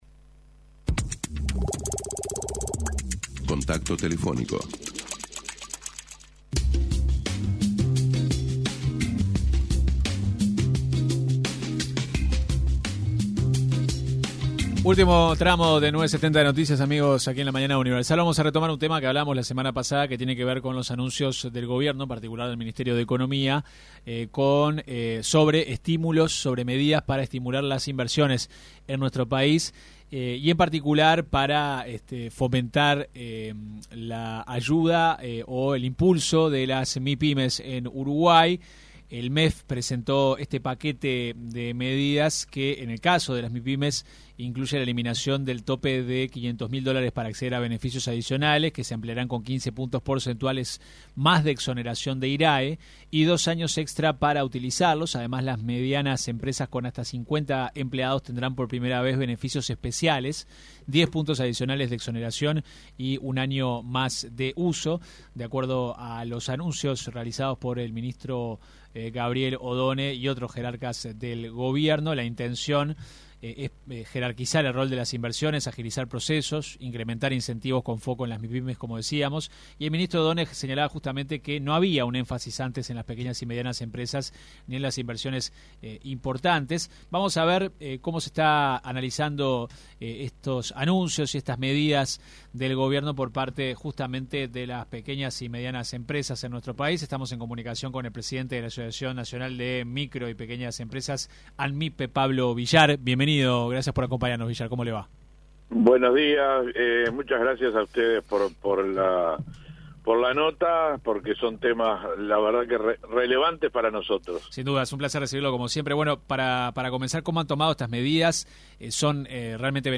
se refirió en diálogo con 970 Noticias, a la medida adoptada por este Gobierno de gravar el IVA a las compras en el exterior, por el efecto “Temu”.